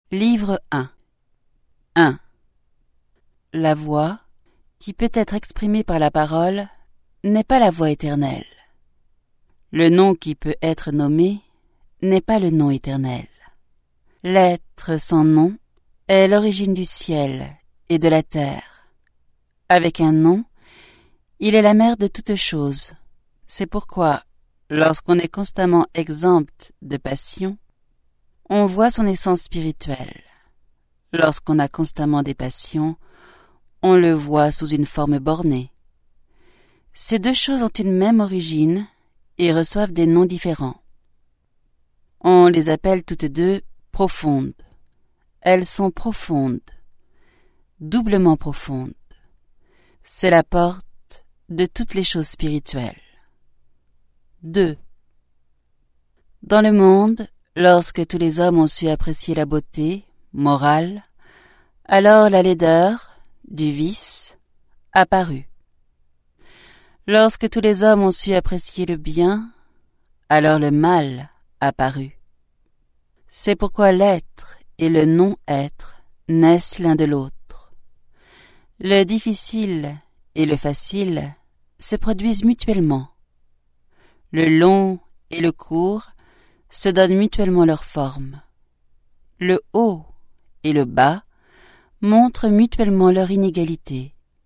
je découvre un extrait - Le livre de la voie et de la vertu de Lao Tseu